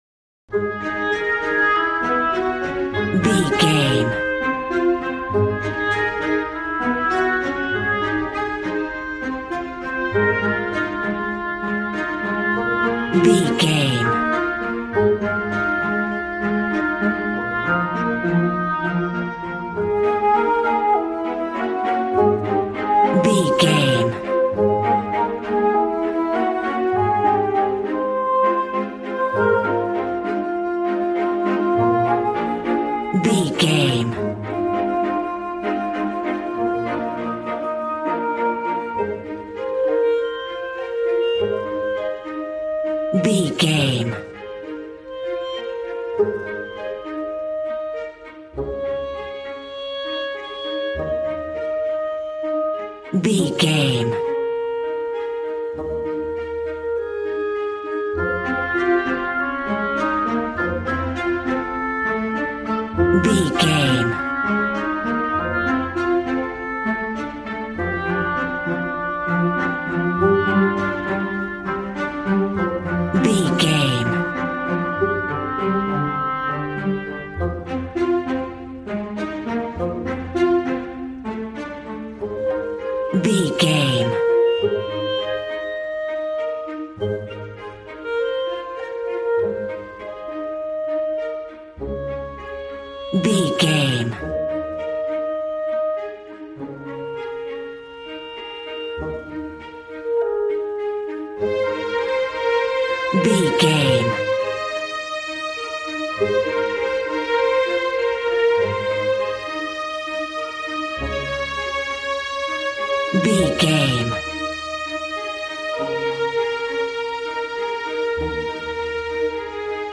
Aeolian/Minor
piano
violin
strings